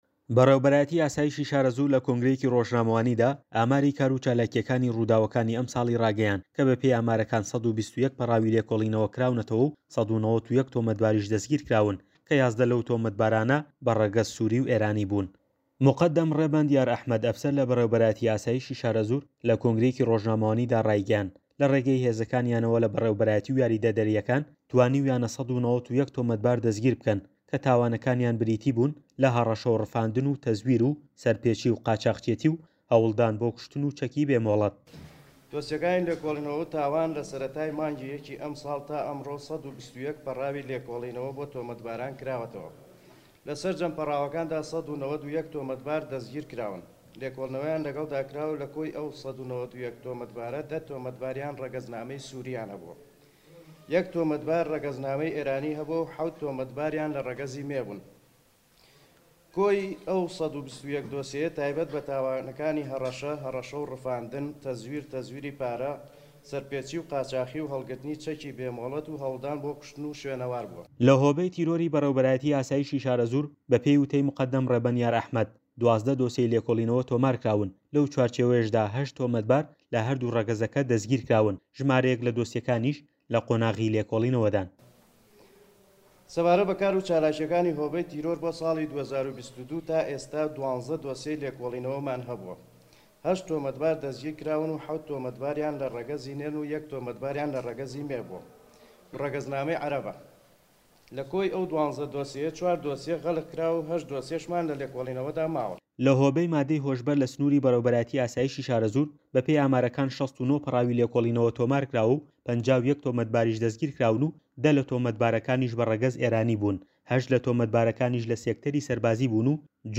ڕاپۆرتی پەیامنێر